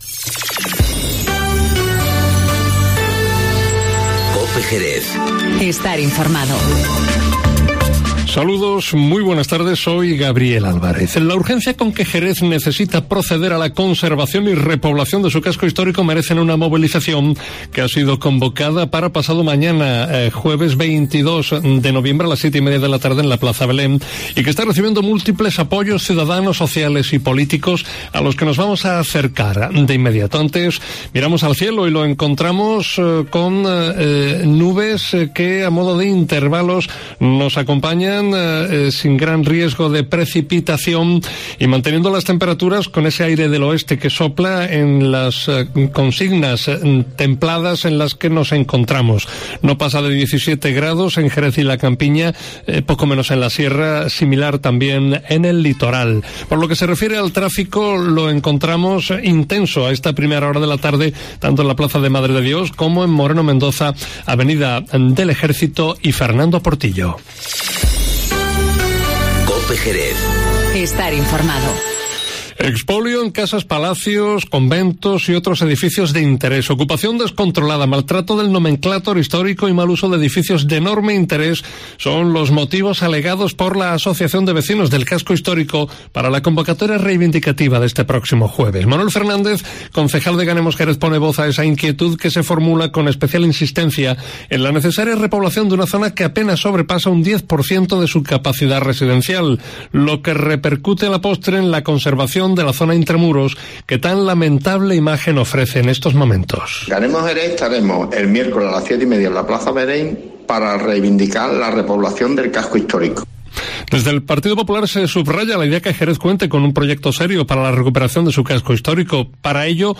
Manuel Fernández, concejal de Ganemos Jerez, pone voz a esa inquietud que se formula con especial insistencia en la necesaria repoblación de una zona que apenas sobrepasa un 10% de su capacidad residencial, lo que repercute en la conservación de la zona intramuros que tan lamentable imagen ofrece.